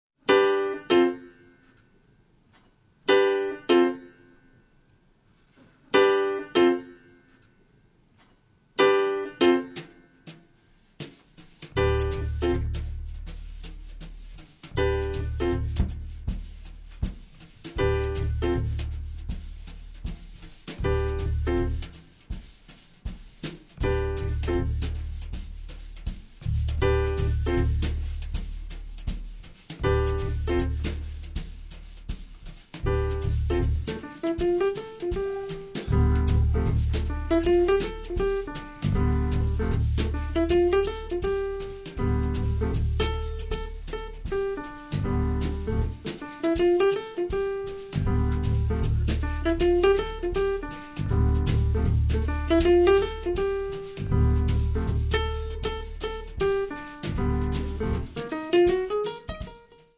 E non manca neanche il trio, l'ultimo suo trio